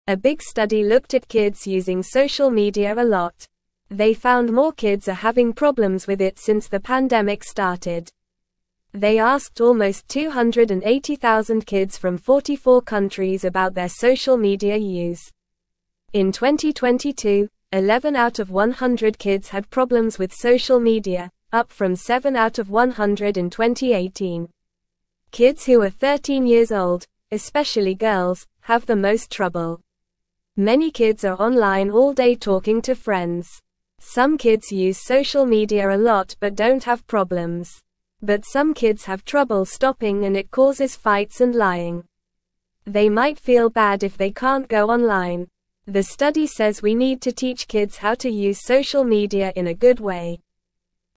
Normal
English-Newsroom-Beginner-NORMAL-Reading-Kids-Using-Social-Media-More-Since-Pandemic-Started.mp3